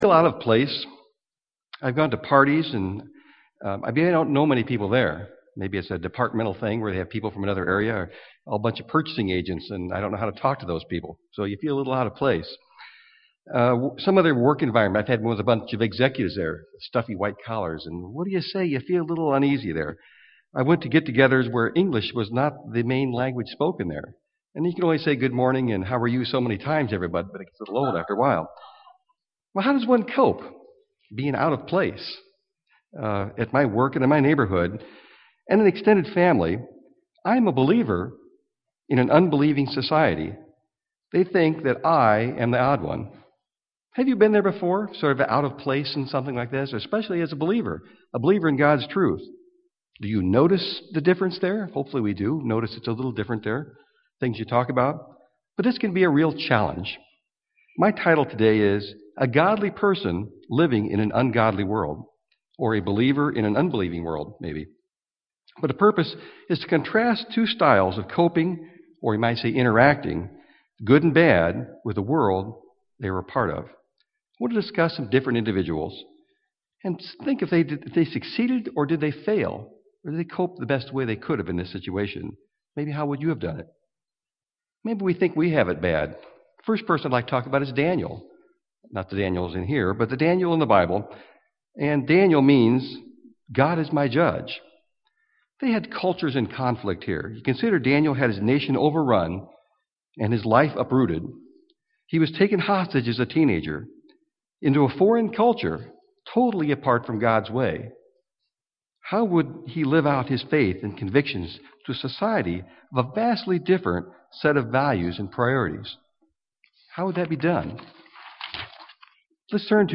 Given in Milwaukee, WI
Print How to live a Godly life in an ungodly world- examples from the life of Daniel UCG Sermon Studying the bible?